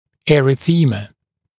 [ˌerɪ’θiːmə][ˌэри’си:мэ]эритема